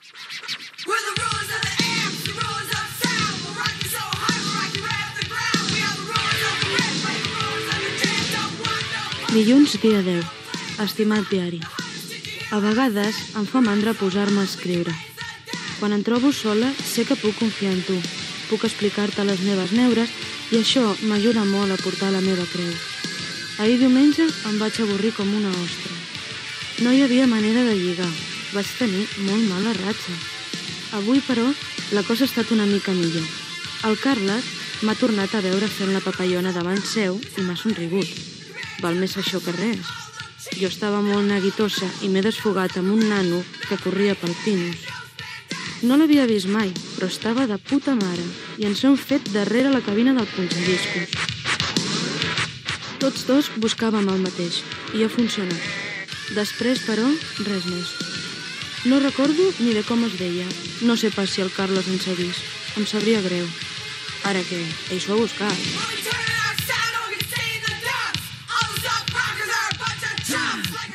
La protagonista llegeix el seu diari on explica les seves relacions personals
Ficció
Fragment extret del programa "Memòries de ràdio", emès per Ràdio 4 i accessible al web RTVE Audio.